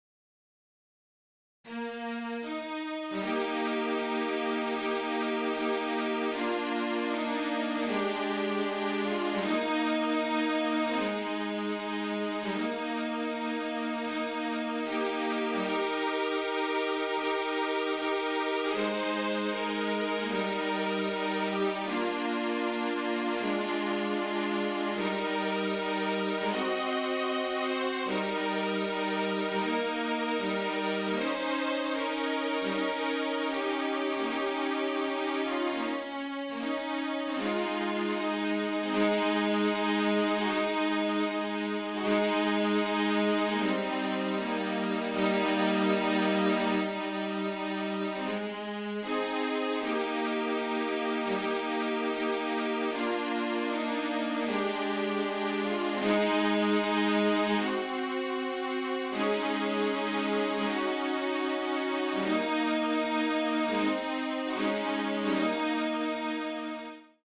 MIDI
(for two violins or violin and viola)